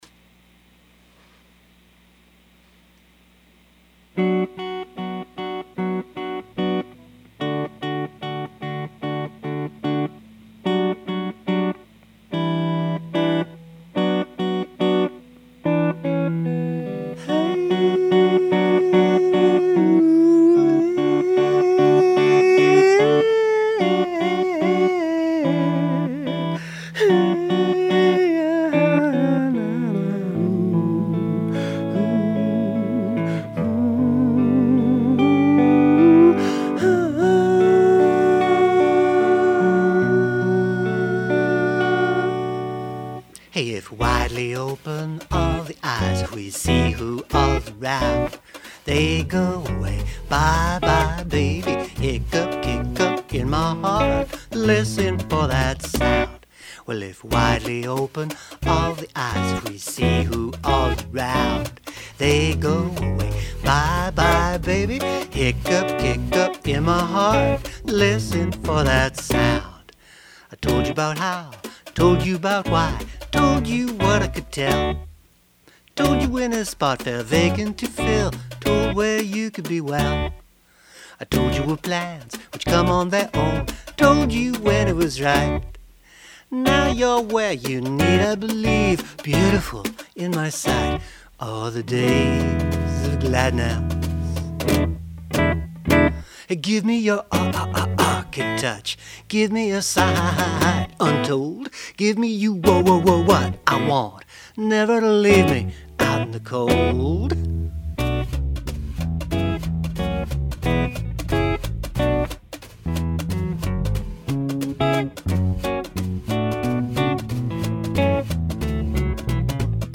Took on comments about space on vocals on this one.